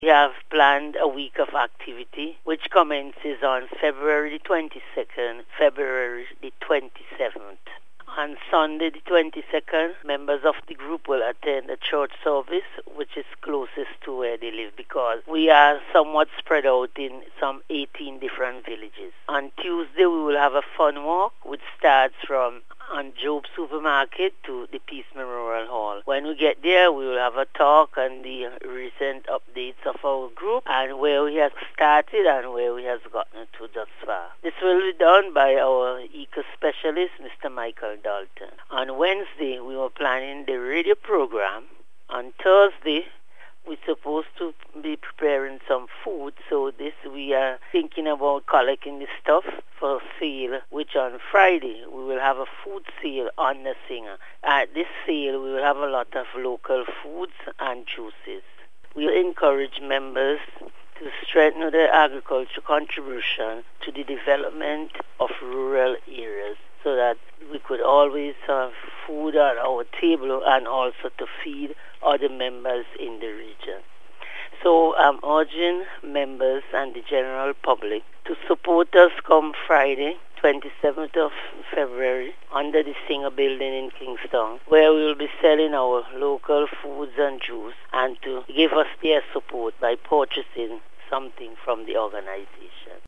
During an interview with NBC news